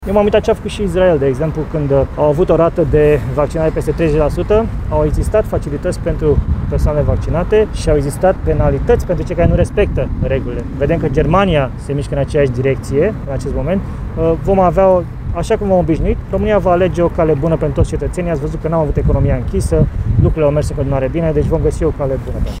04mai-12-Citu-m-am-uitat-la-Israel-cum-a-facut.mp3